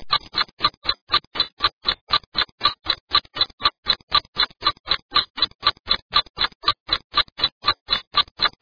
RELOJ